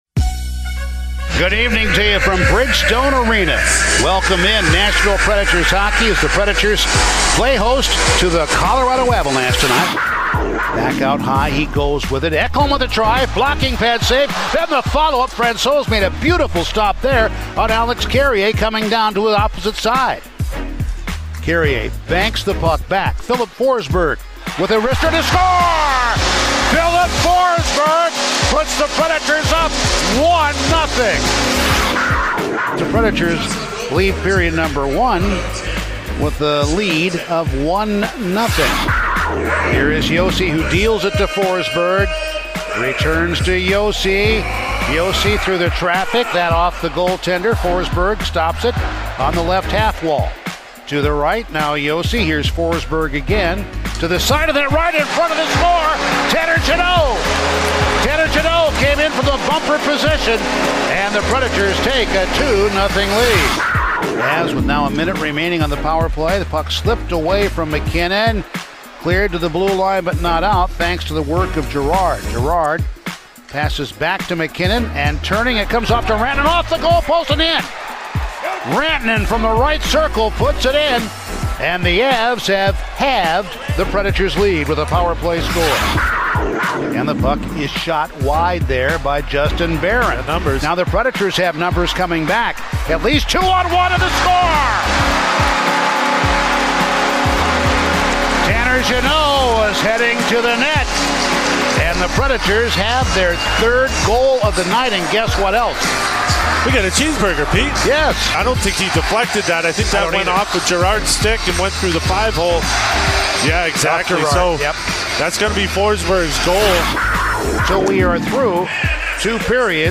Full radio highlights from the Preds' 5-2 win over the Avs